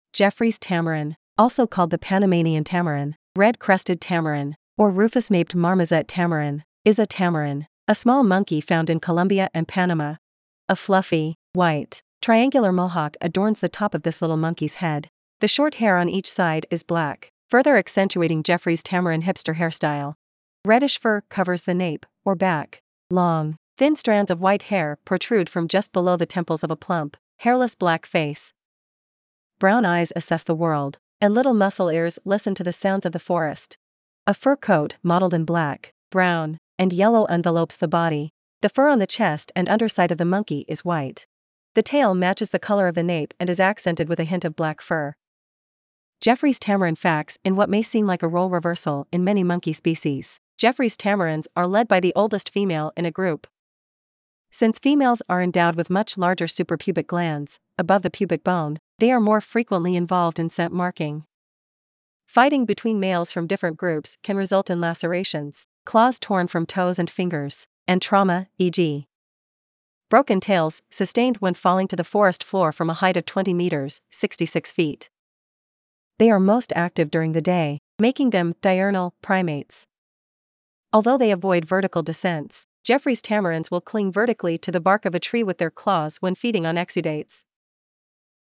Geoffroy’s Tamarin
Geoffroys-Tamarin.mp3